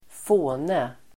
Ladda ner uttalet
Uttal: [²f'å:ne]